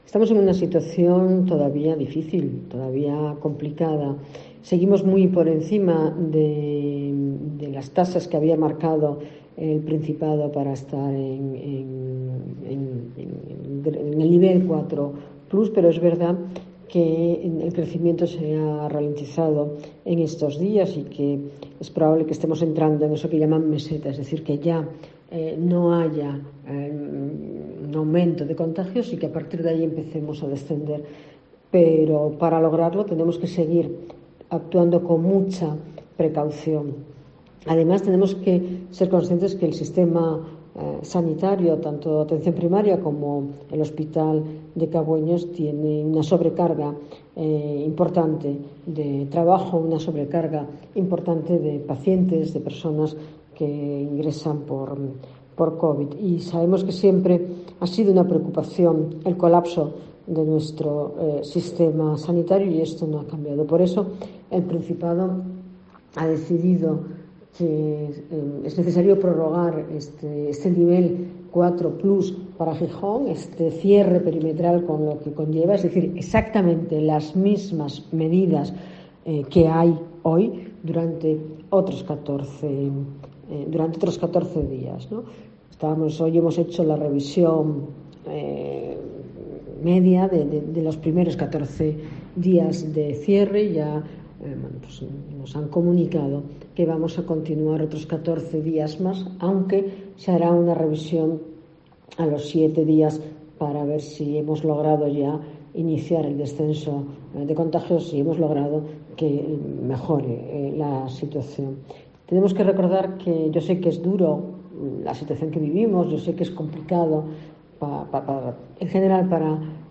Ana González, alcaldesa de Gijón: La situación todavía es difícil